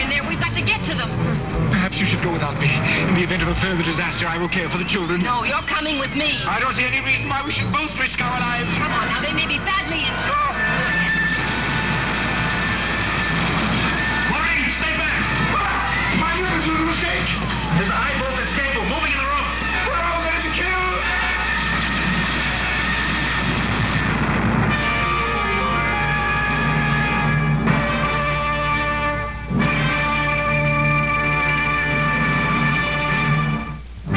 John saying to Maureen and Dr. Smith: "There's a high voltage cable moving in the room"